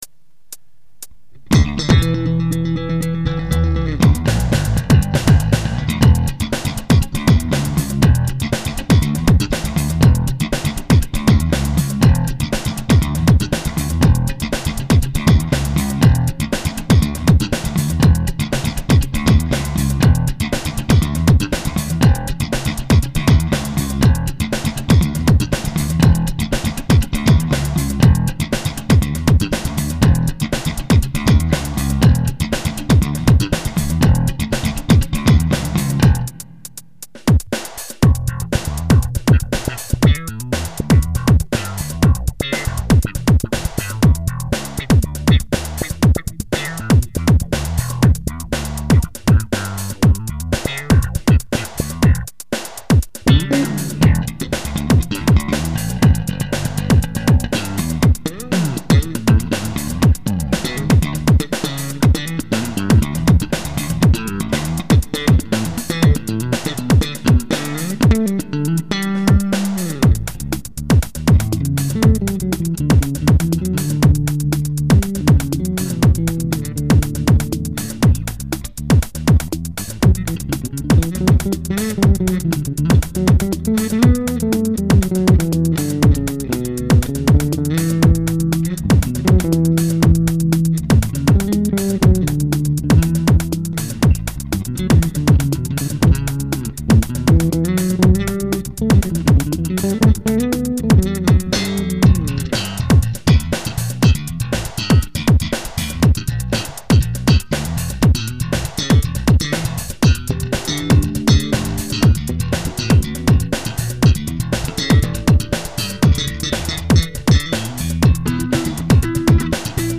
この音は・・・ジャズベですね。
slap001.mp3